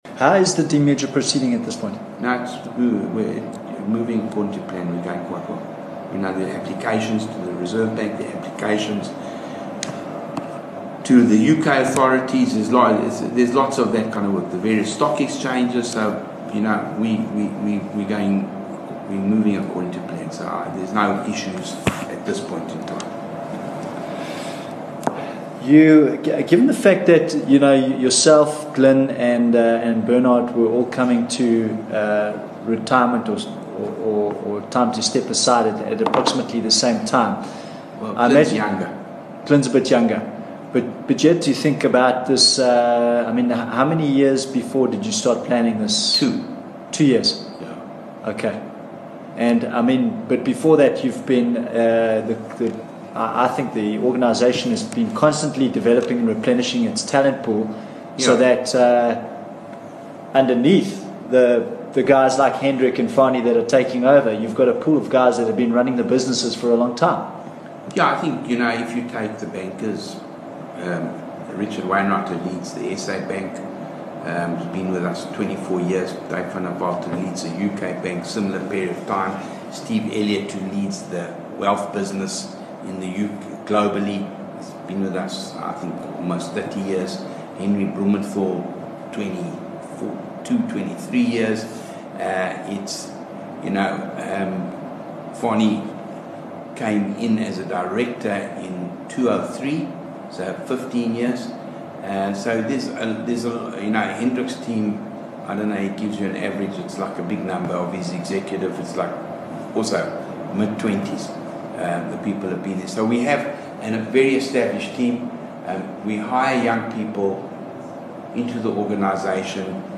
4 Dec Stephen Koseff interview - Part 2